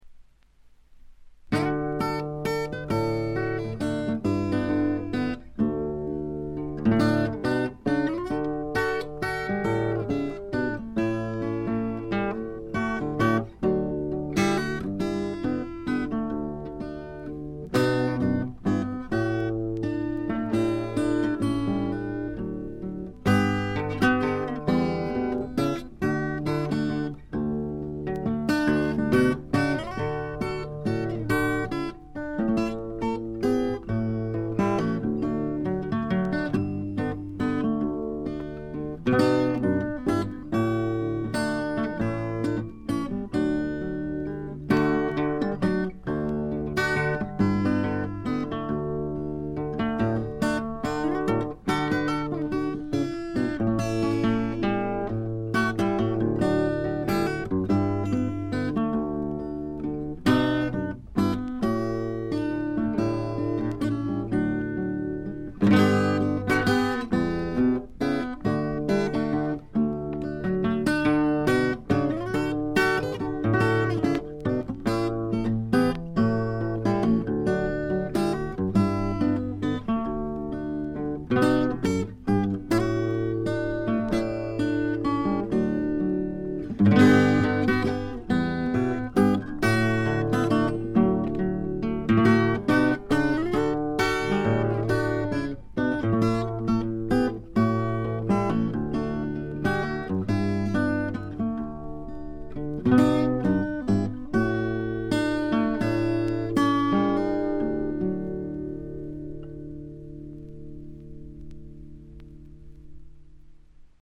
軽微なチリプチがわずかに出る程度。
試聴曲は現品からの取り込み音源です。
guitar, bass, mandolin, vocals